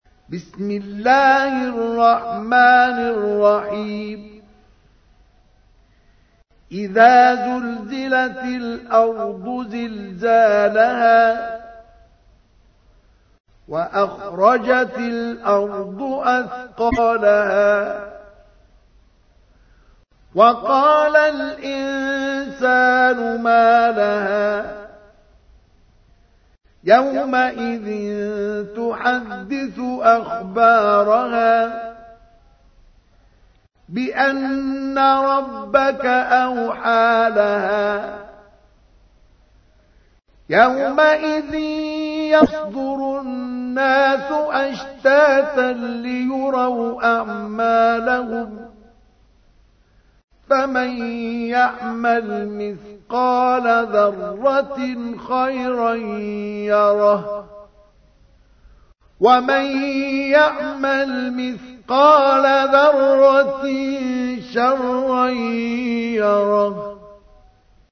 تحميل : 99. سورة الزلزلة / القارئ مصطفى اسماعيل / القرآن الكريم / موقع يا حسين